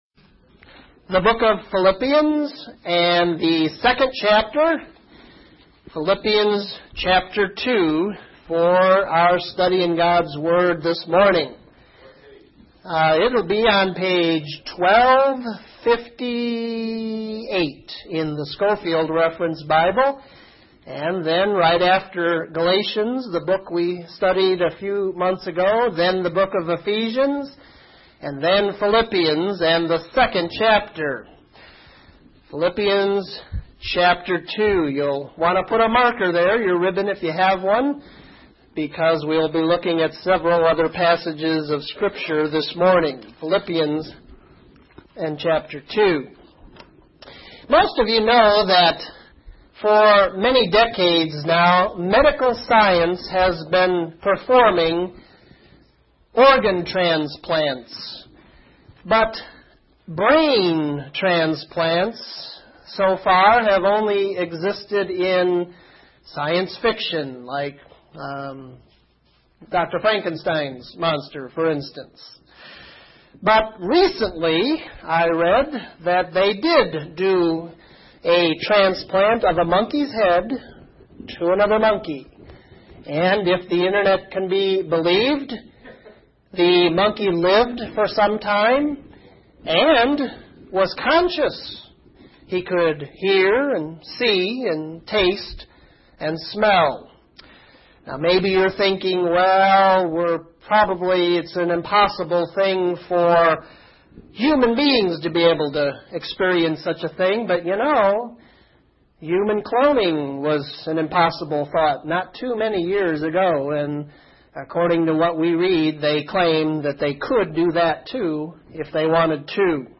Lesson 10: Philippians 2:5-8